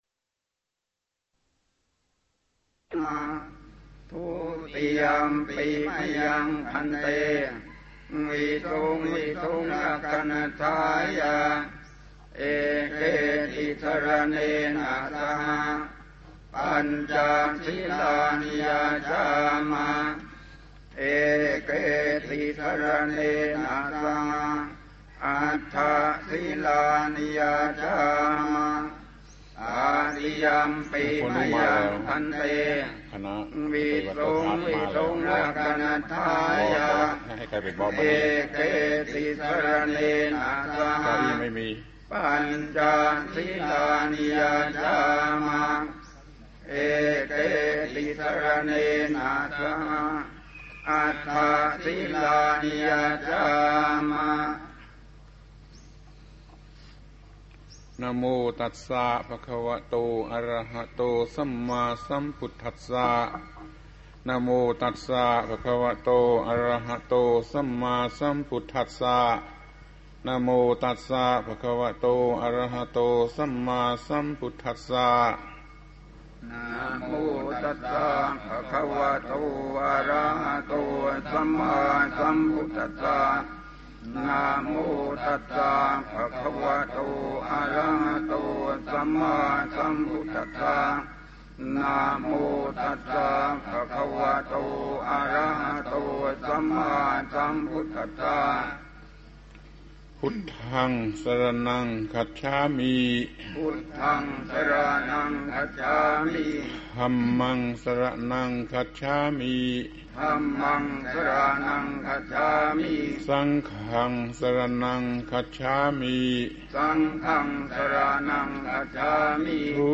พระธรรมโกศาจารย์ (พุทธทาสภิกขุ) - อนุโมทนาและอวยพรปีใหม่ก่อนตักบาตร ปี 2524 พูดวันตักบาตรปีใหม่